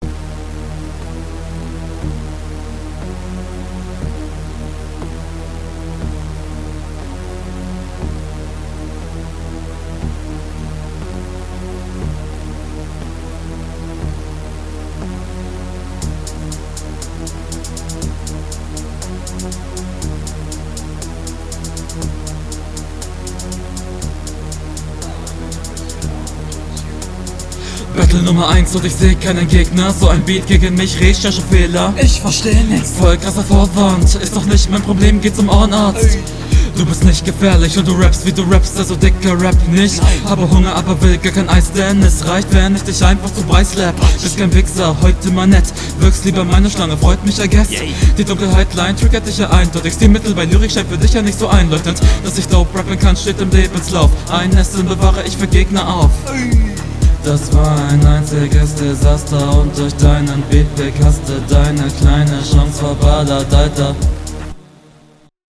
Flow: Du stehst kein bisschen deinem Gegner hinterher Text: Ohrnarzt . die line fand ich …